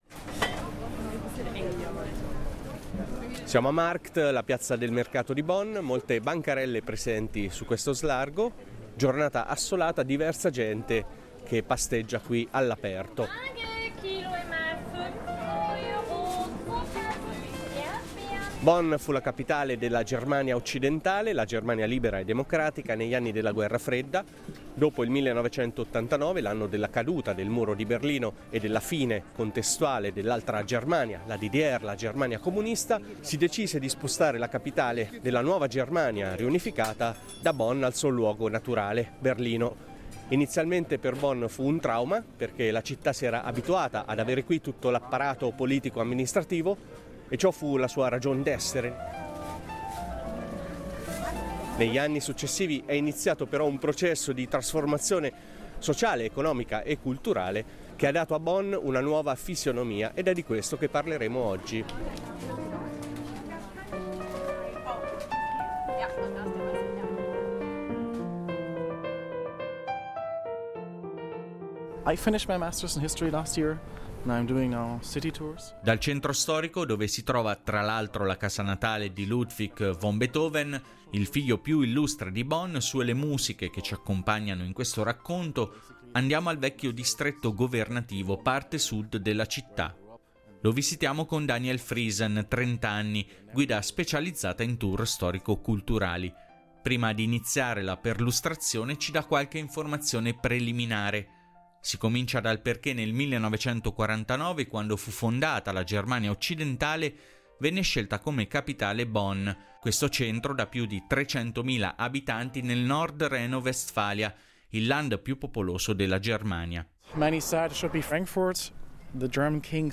Alcune delle interviste sono tratte dal progetto “Nati dopo l'89” .